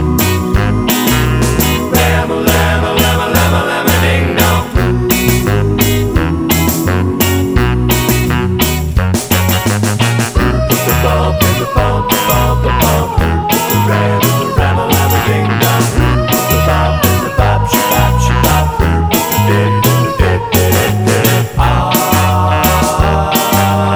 Talking Section Removed Rock 'n' Roll 2:10 Buy £1.50